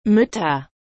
●「ʏ」はドイツ語の「y」より唇の力を抜いた感じです。
発音　: